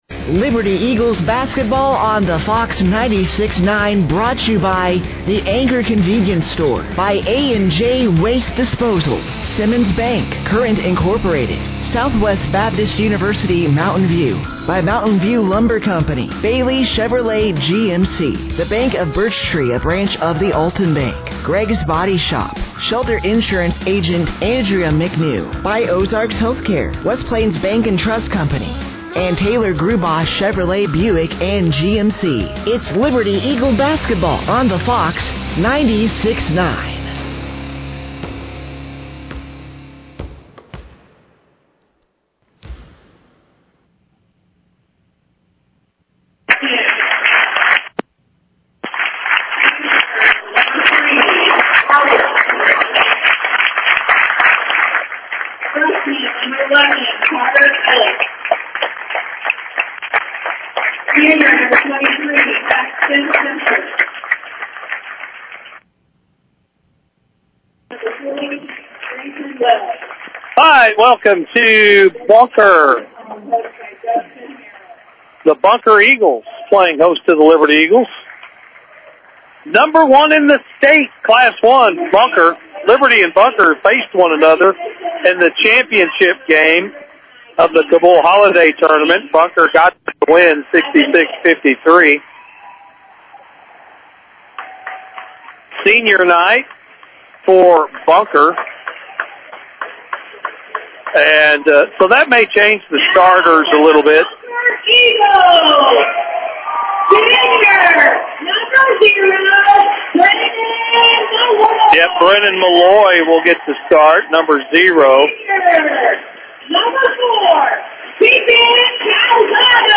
Game Audio Below: